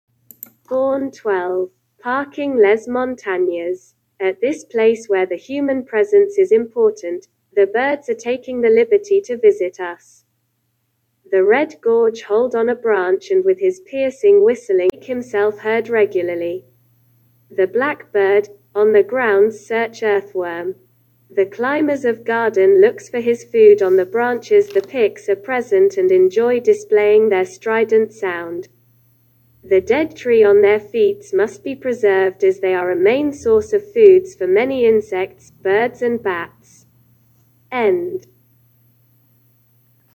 At this place where the human presence is important, the birds are taking the liberty to visit us. The red-gorge hold on a branch and with his piercing whistling make himself heard regularly. The black bird, on the grounds search earthworm, The climbers of garden looks for his food on the branches The pics are present and enjoy displaying their strident sound.